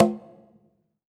PBONGO LW.wav